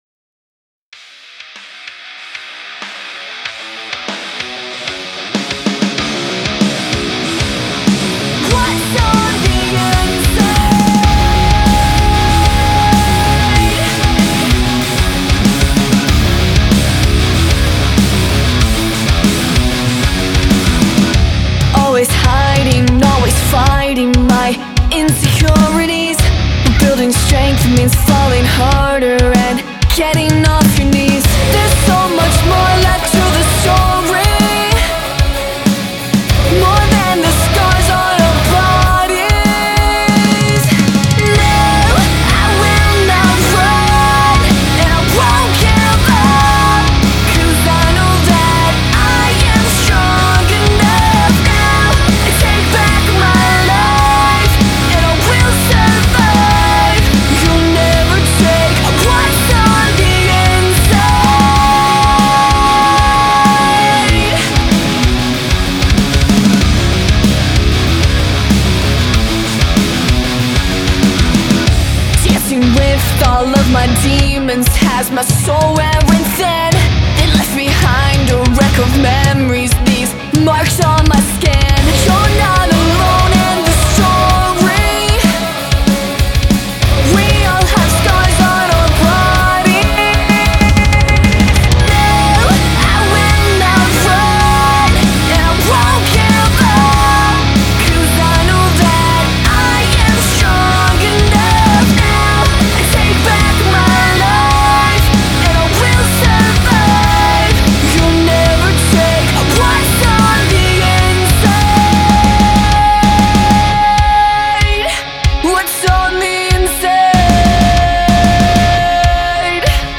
the glam rock trio of sisters from Jacksonville
lead guitars and vocals
bass, keys and vocals
drums